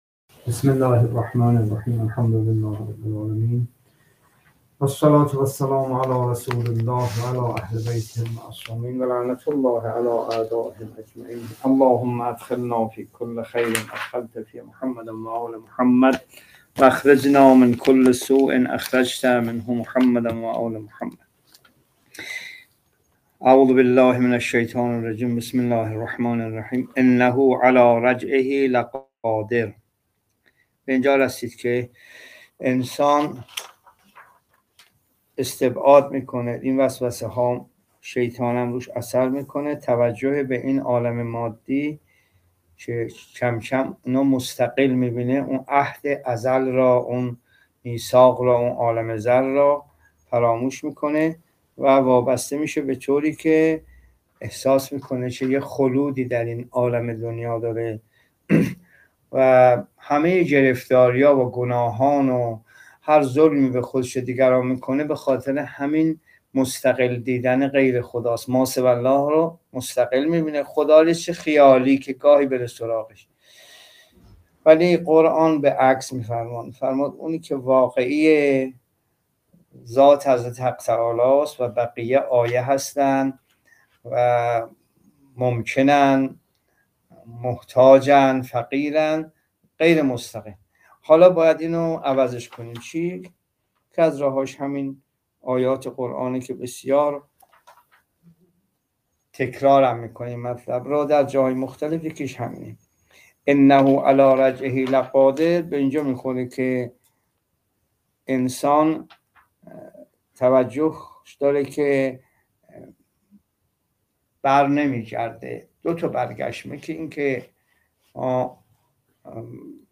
جلسه تفسیر قرآن (13) سوره طارق